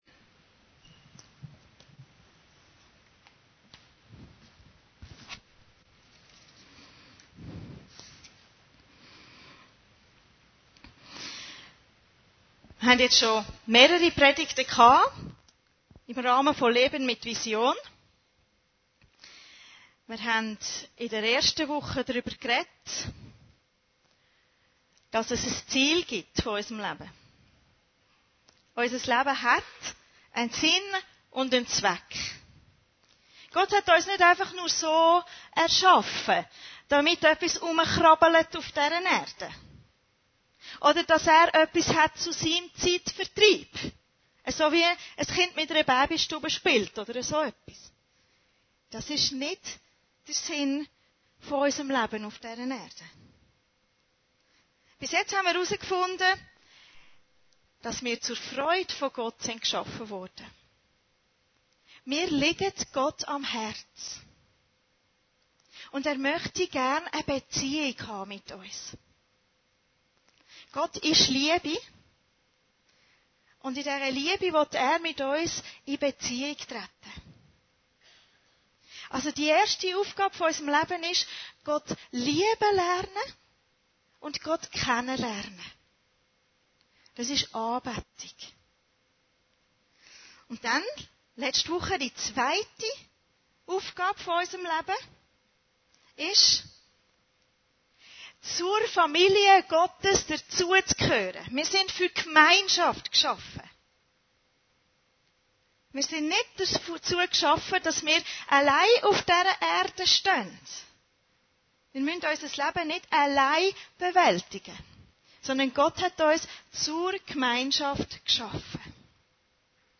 Predigten Heilsarmee Aargau Süd – Christus ähnlicher werden